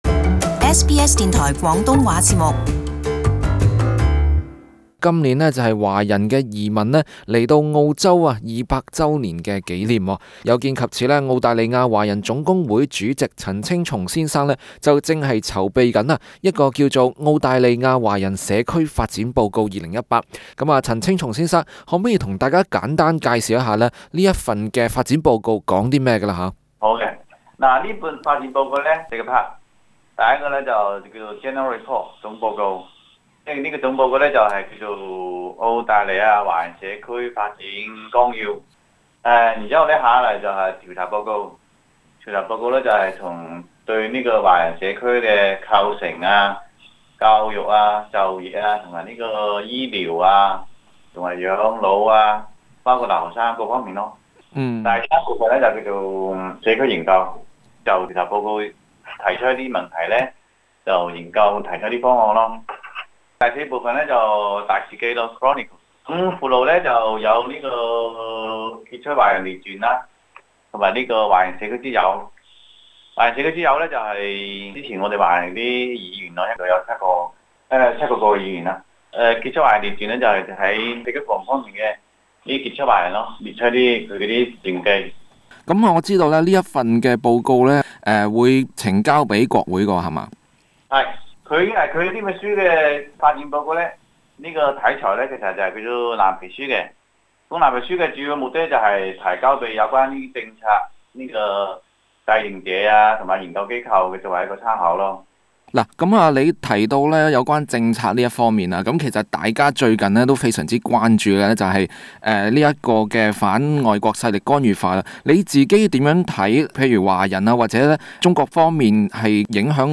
【時事專訪】華人移民澳洲200年